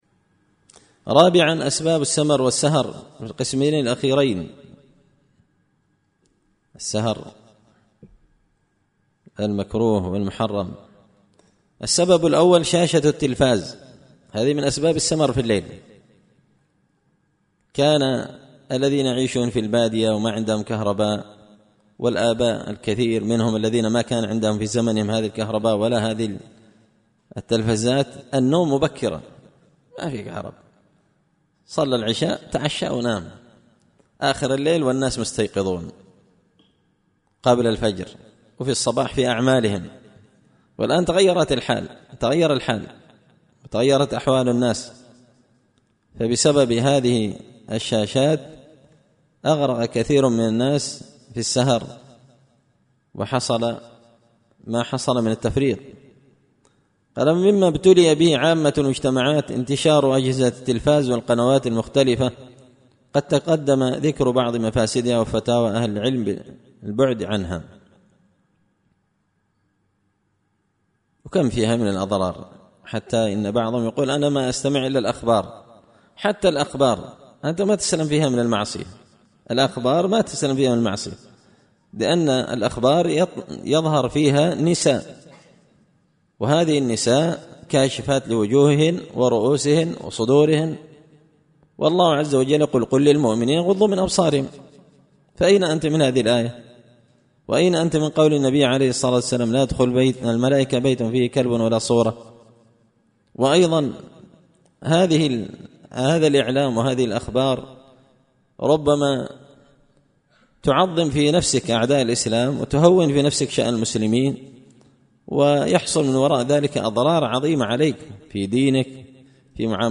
إمتاع النظر بأحكام السمر والسهر ـ الدرس الثاني والعشرون